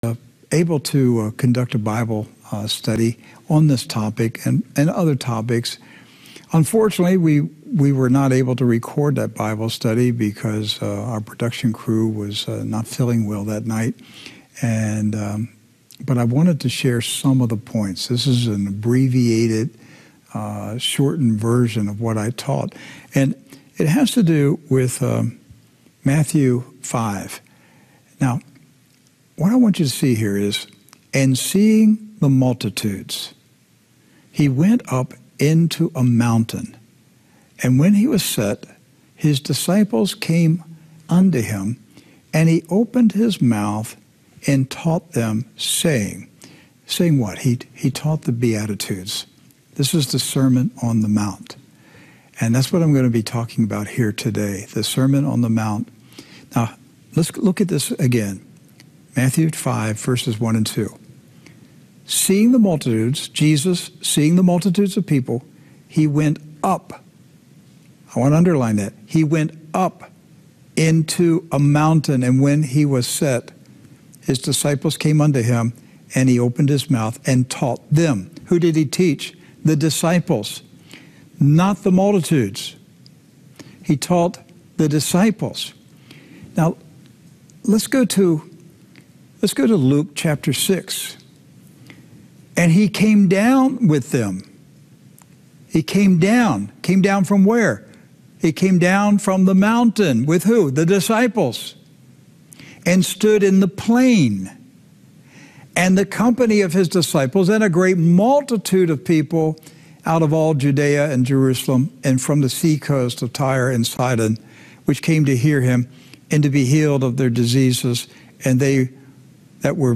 The 10 Commandments the be attitudes I LOVE THIS Sermon Jesus spoke and is recorded in Matthew. Each beatitude looks at different circumstances of life and how all Christians are blessed